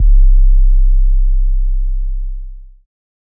808 (KickOn Top).wav